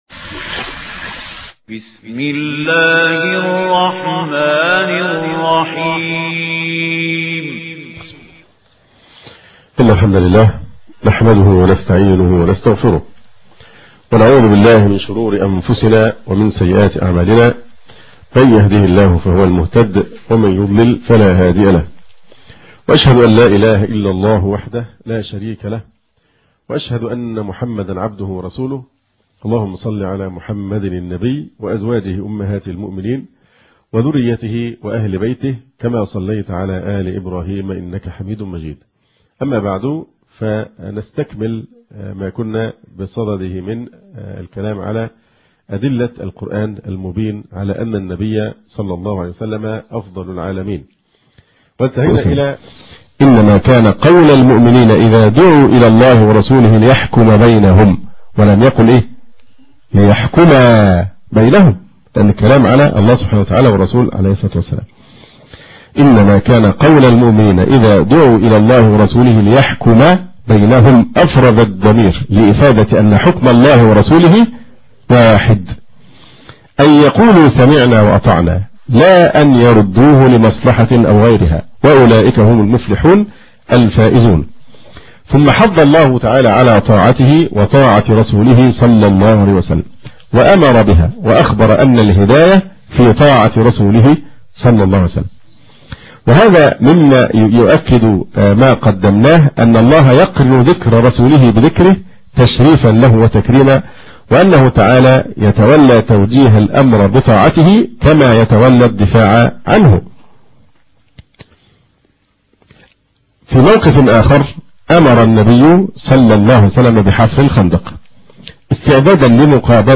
المحاضرة السادسة